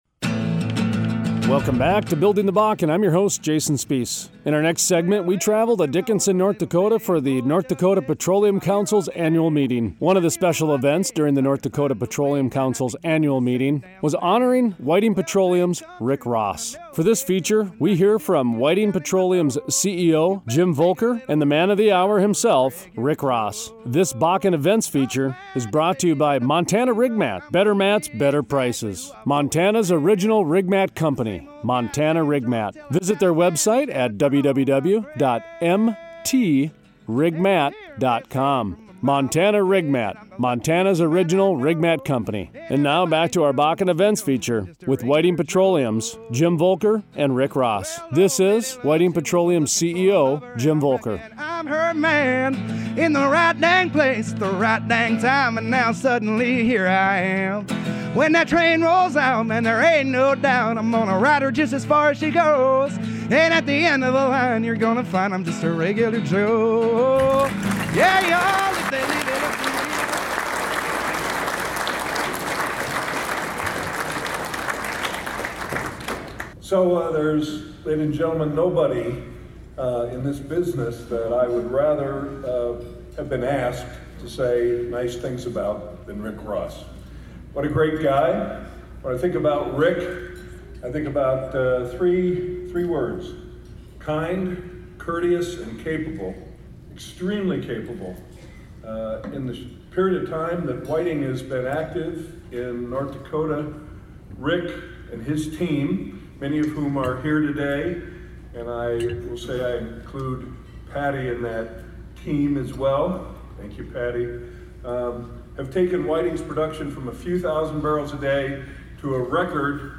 Interviews
Music Montage